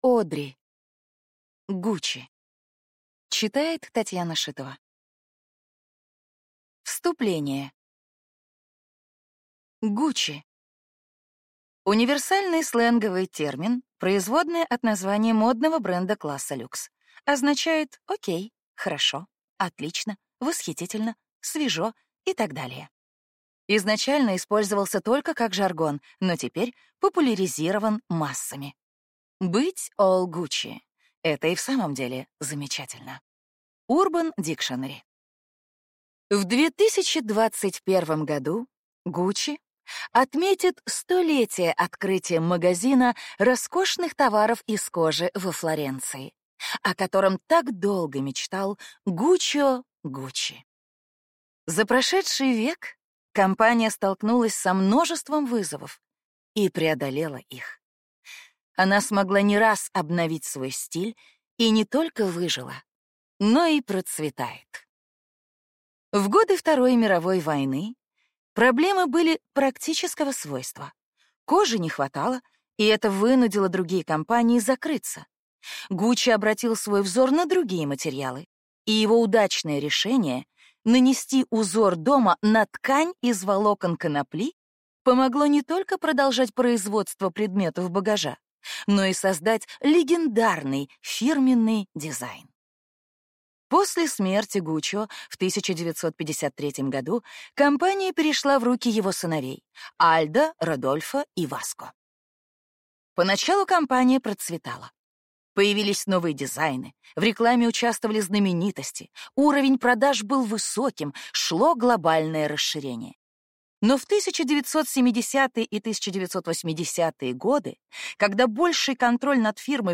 Аудиокнига GUCCI. История модного дома | Библиотека аудиокниг